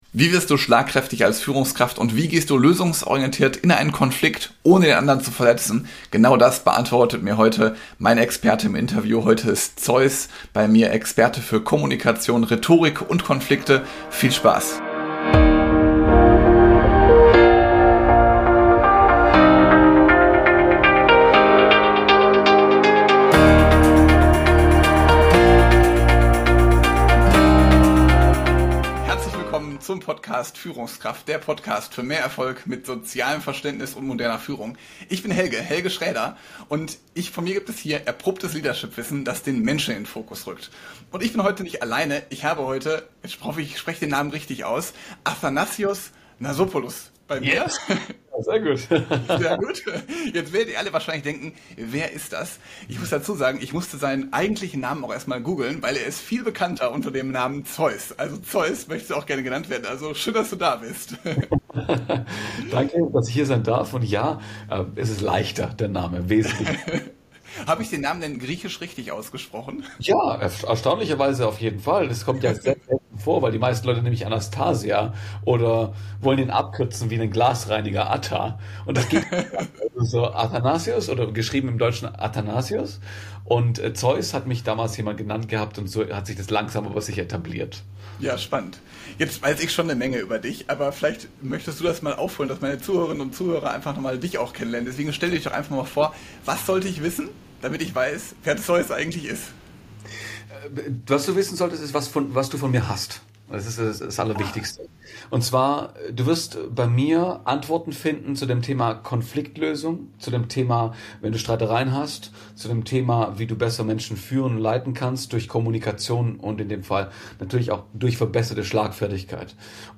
Endlich schlagfertig als Führungskraft - Interview